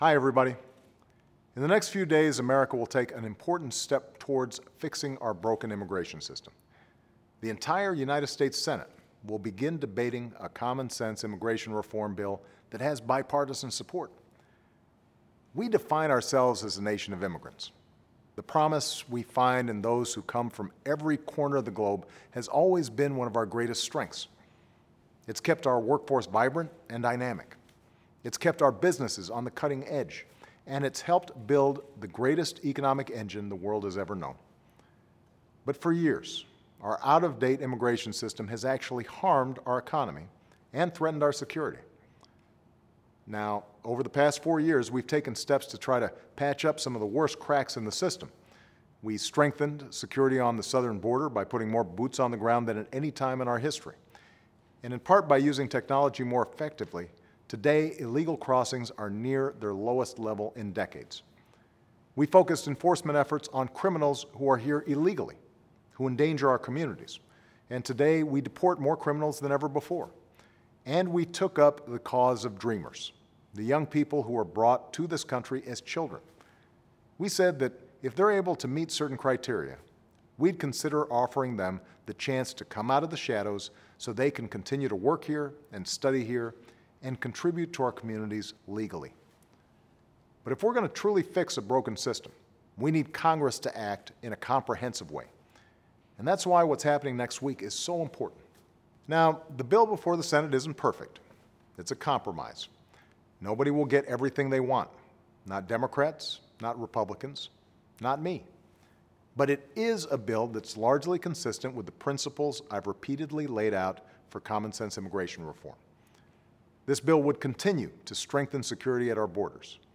Weekly Address: Time to Pass Commonsense Immigration Reform